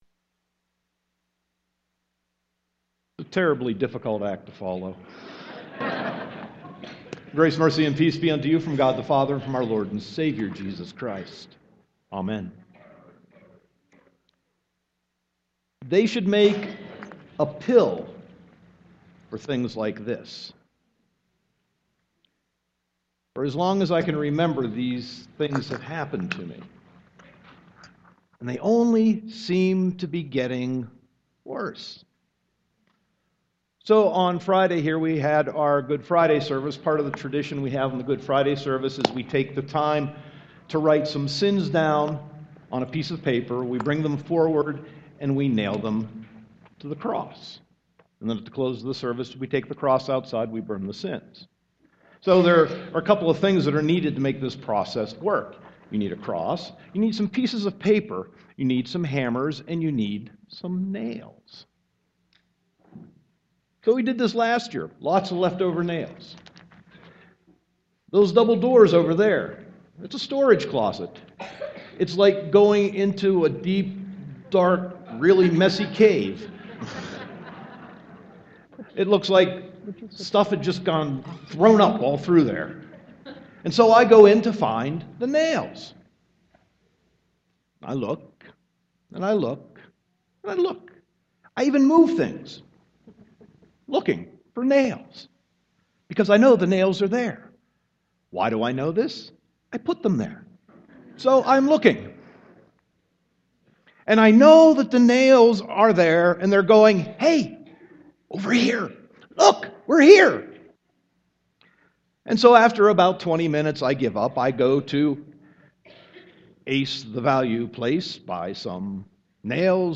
Sermon 4.5.2015